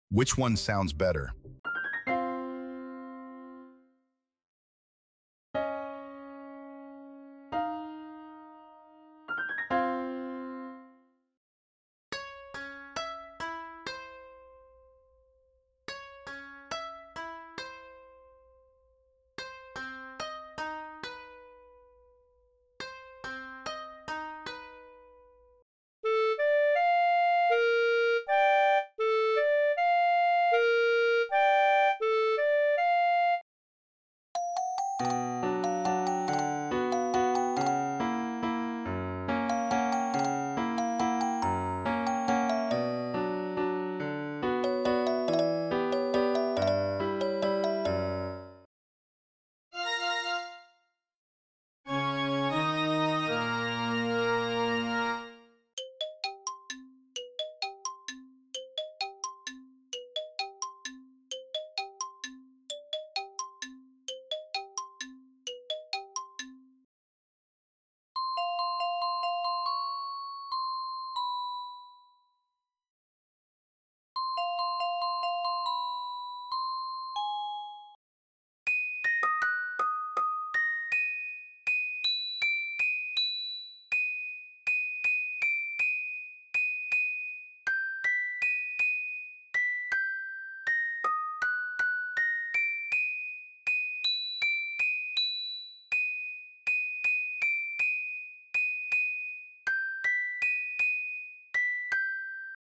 on Different Instruments